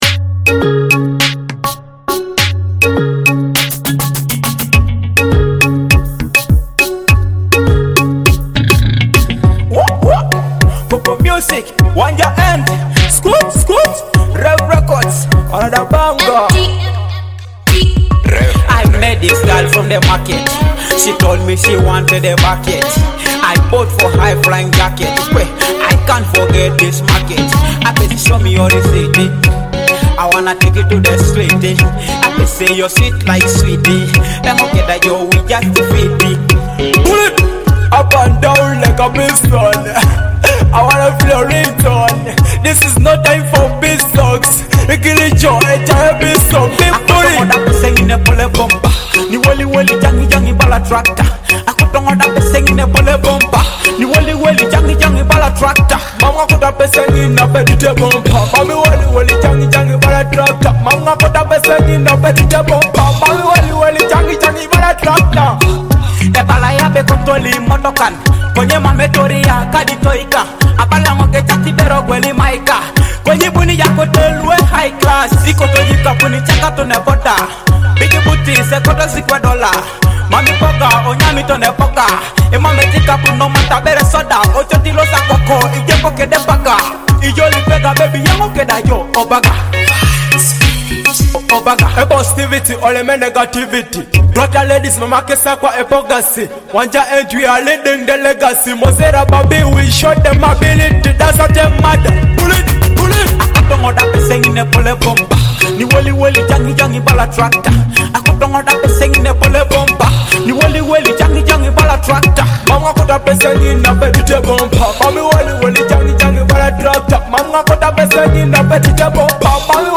Blending Afrobeat and dancehall vibes